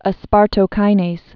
(ə-spärtō-kīnās)